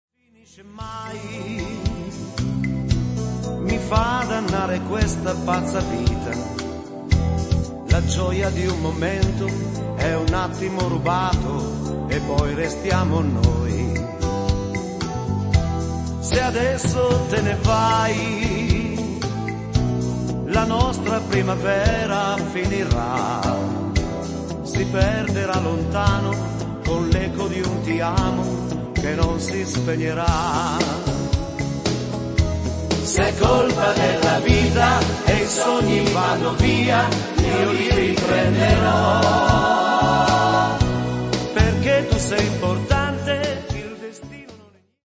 valzer lento